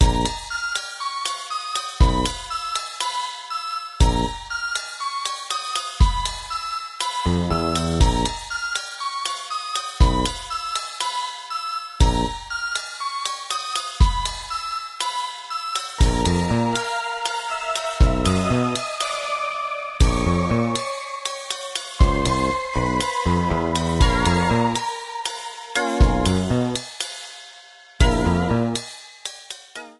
trimmed & added fadeout You cannot overwrite this file.